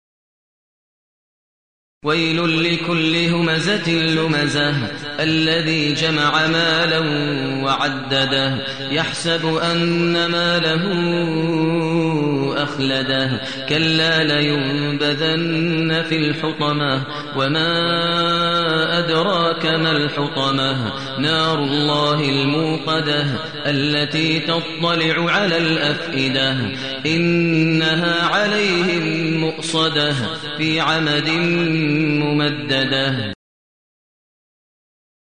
المكان: المسجد النبوي الشيخ: فضيلة الشيخ ماهر المعيقلي فضيلة الشيخ ماهر المعيقلي الهمزة